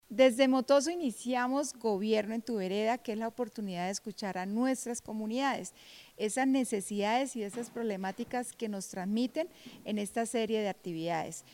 Yulia Rodríguez, Acaldesa de Girón.mp3